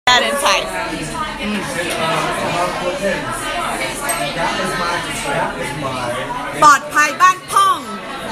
UEThai-Curse.mp3